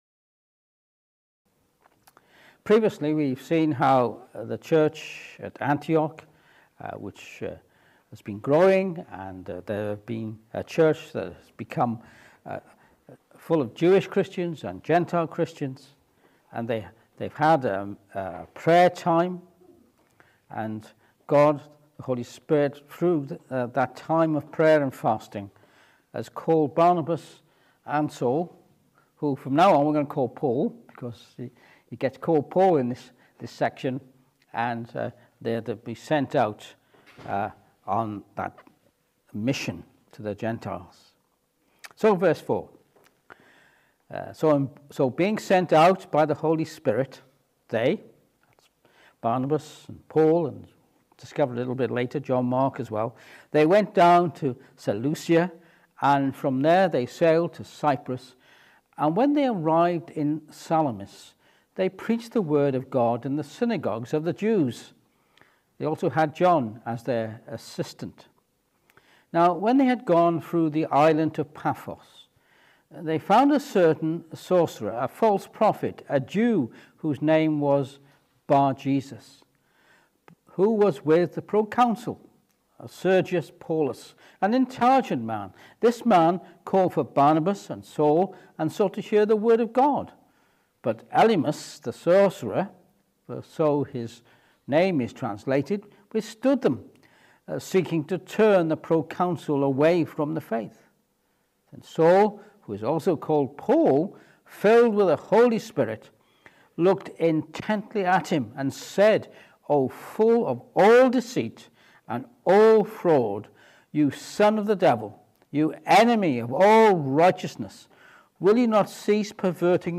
4-13 Service Type: Evening Service This evening we join Paul on his first missionary journey as he sails to Cyprus.